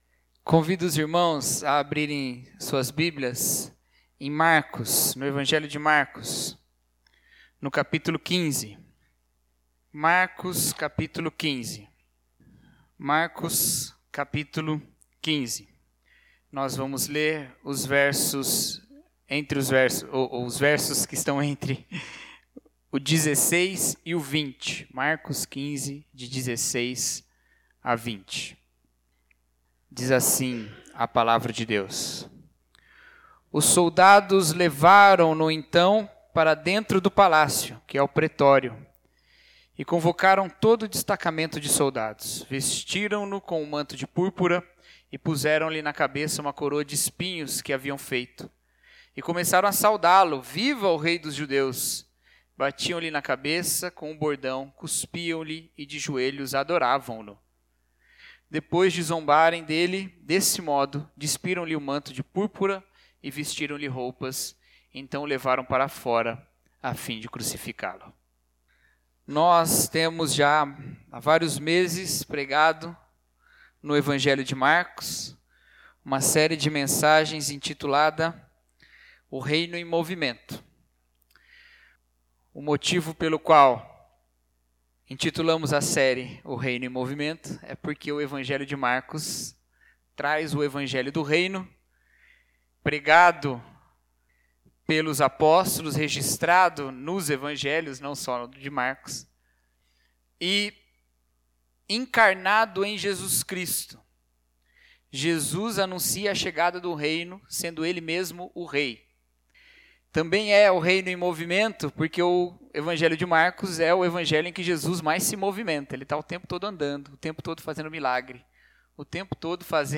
O Reino em Movimento Mensagem: O Reino de Cristo Texto Base: Mc. 15. 16-20 Pregador